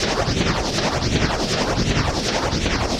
RI_RhythNoise_80-04.wav